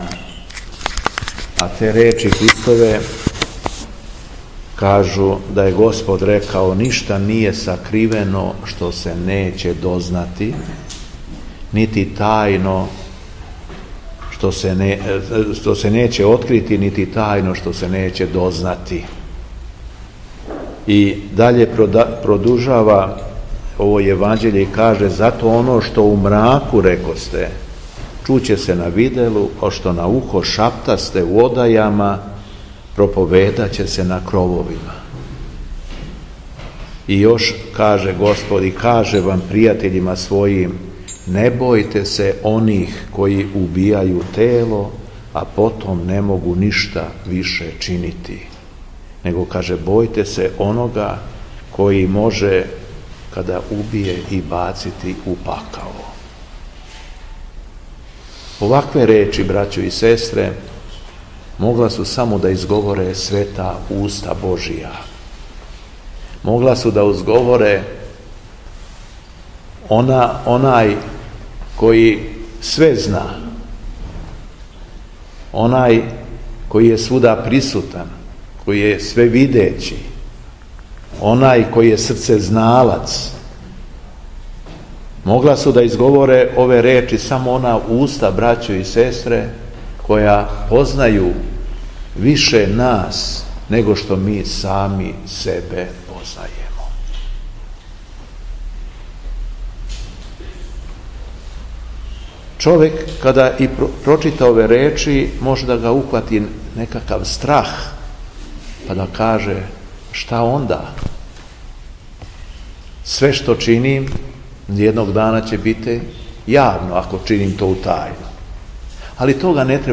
Беседа Његовог Преосвештенства Епископа шумадијског г. Јована